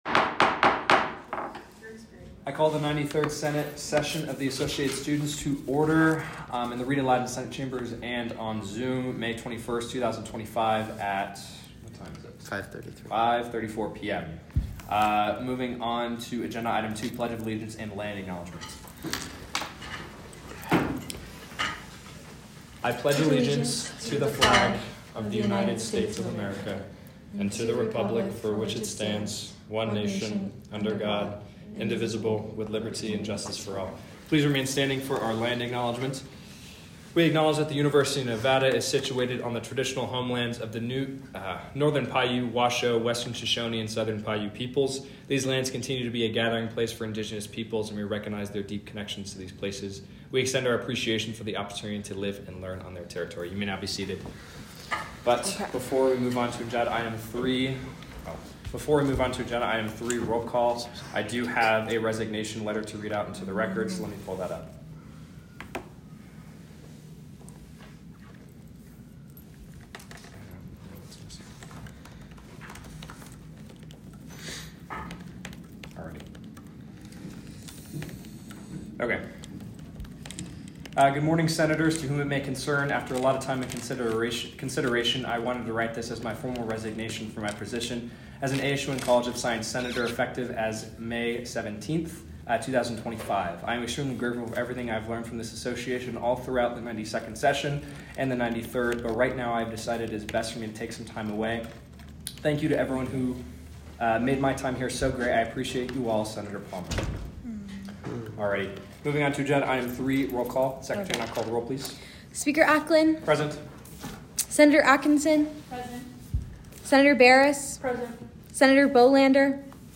Location : Rita Laden Senate Chambers